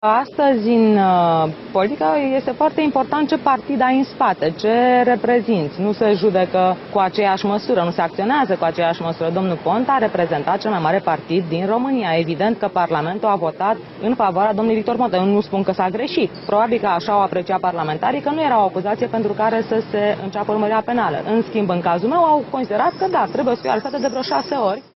Aflată sub control judiciar din cauza acuzațiilor de corupție care i se aduc în dosarul Gala Bute, Elena Udrea a declarat la ieșirea din Secția de Poliție unde este obligată să se prezinte, că  în România e important ce partid ai în spate atunci când vine vorba de ridicarea imunității parlamentare și începerea urmăririi penale.  Ea s-a referit astfel la respingerea cererii procurorilor de începere a urmăririi penale în cazul premierului Victor Ponta.